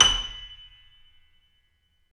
Index of /90_sSampleCDs/Roland - Rhythm Section/KEY_YC7 Piano mf/KEY_mf YC7 Mono
KEY F#6 F 0O.wav